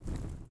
Flare01.ogg